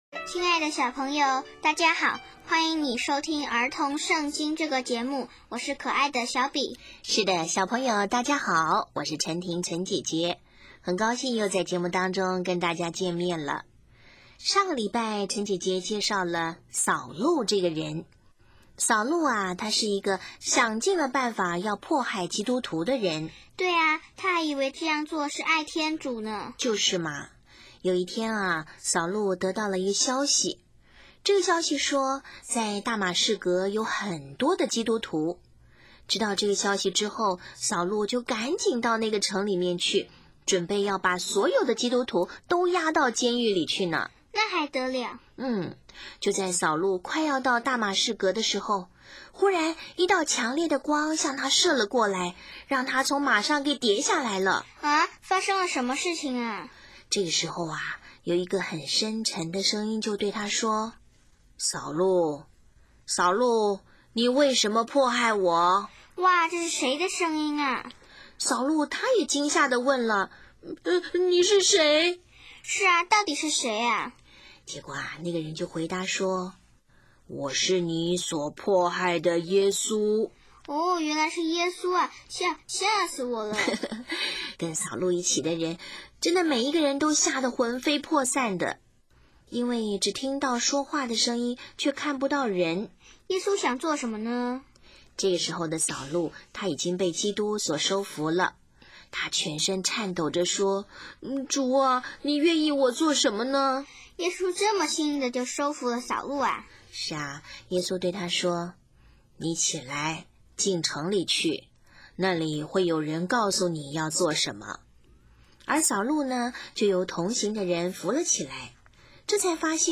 【儿童圣经故事】76|保禄迫害教会与皈化